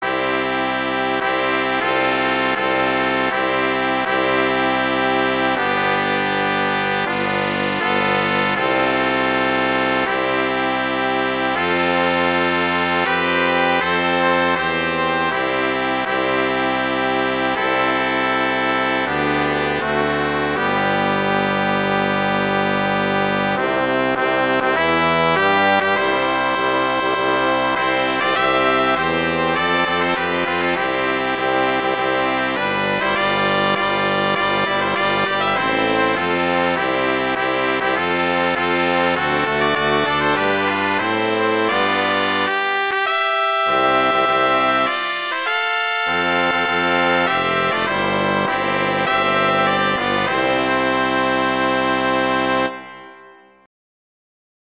C major
♩=100 BPM